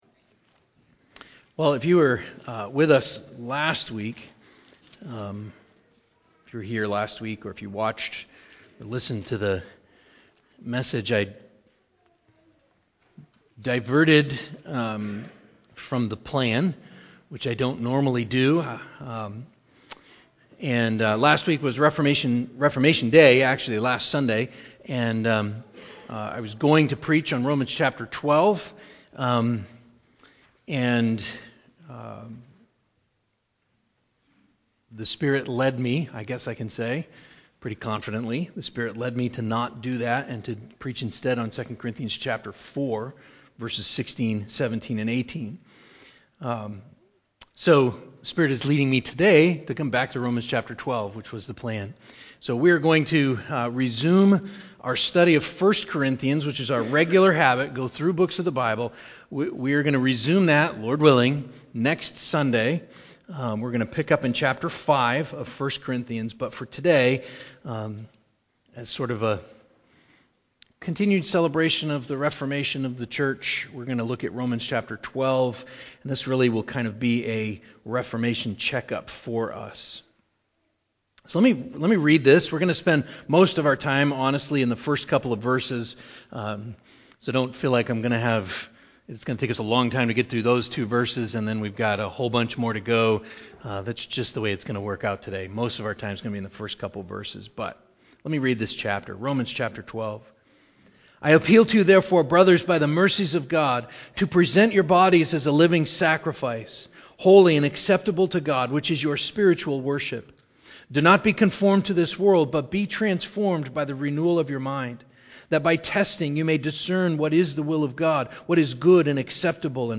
11.7.21-sermon-CD.mp3